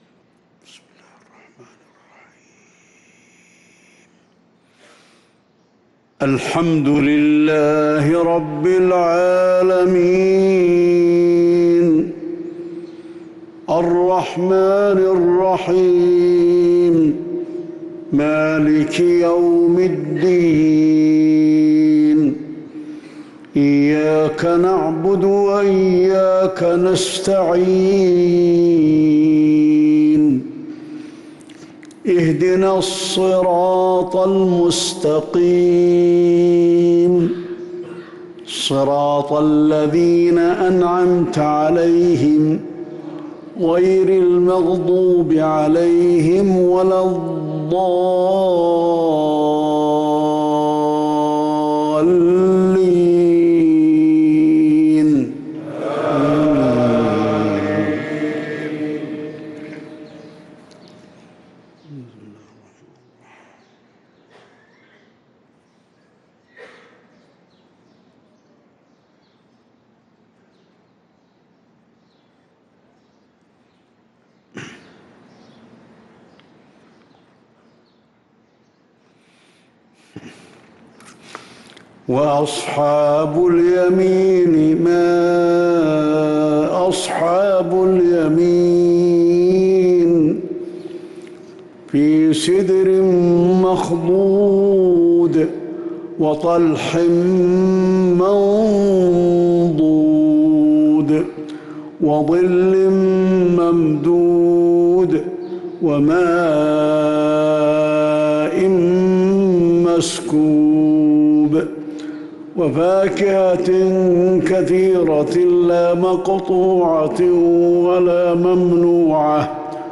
فجر الأربعاء 5 محرم 1444هـ من سورة الواقعة | Fajr prayer from Surat Al-Waqia 3-8-2022 > 1444 🕌 > الفروض - تلاوات الحرمين